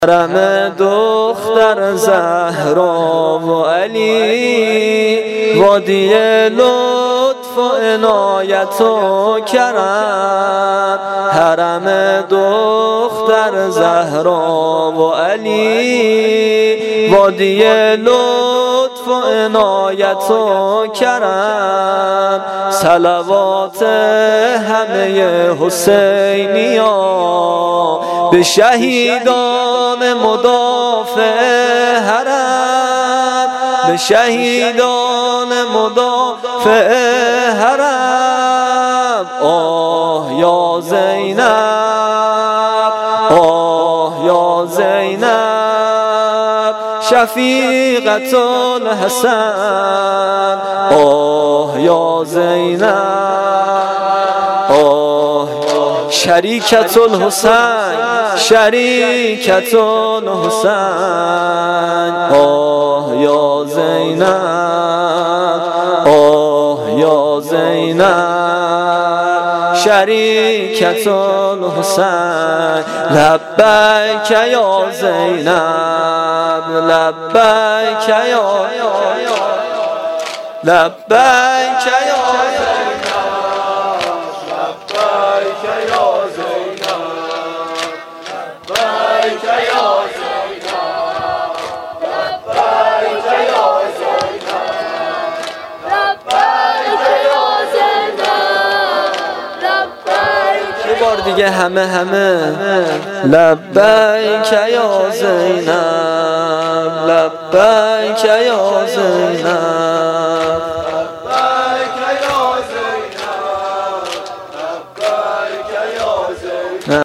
فایل های صوتی و متن اشعار شب نهم محرم الحرام 1395 - 1438 (شب تاسوعای حسینی) اجرا شده در هیئت جواد الائمه (علیه السلام) کمیجان